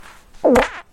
Download Shart sound effect for free.
Shart